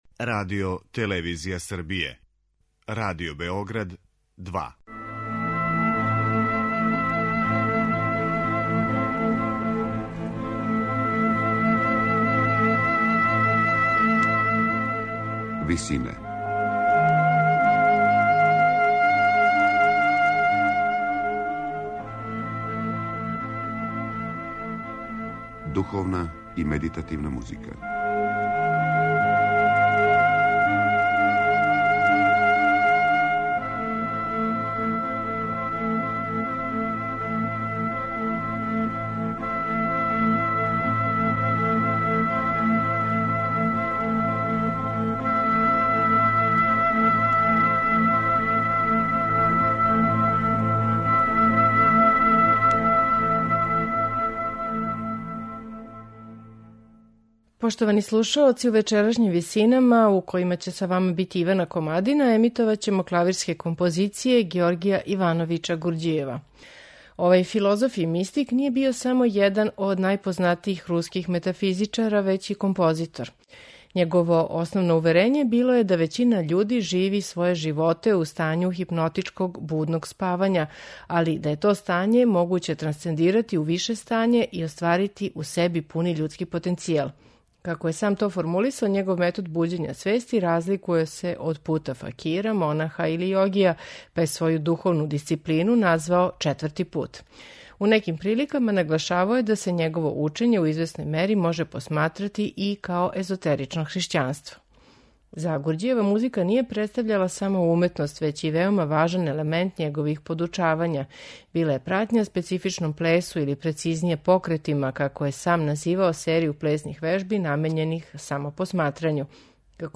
Клавирске композиције